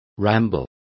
Complete with pronunciation of the translation of ramble.